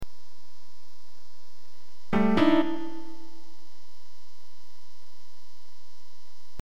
Each word is played as one or more chords of these notes, using the Grand Piano instrument.
If a gallows character ("h" or "k") appears in the word, then the notes preceding the gallows character are played as a chord, followed shortly by the notes after the gallows character, but shifted by either 1 octave ("h") or two octaves ("k").